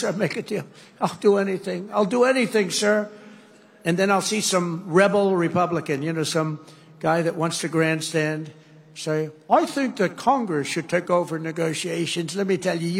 最もリアルなドナルド・トランプ音声AI
音声合成
象徴的な抑揚
当社のAIは、ドナルド・トランプ特有の抑揚と修辞スタイルを完璧に表現する、本物の人間のようなトーンを提供します。